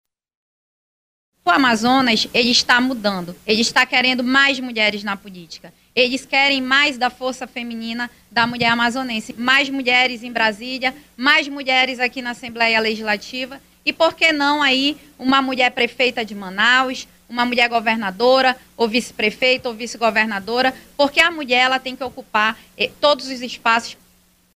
Sonora-Joana-Darc-deputado-estadual.mp3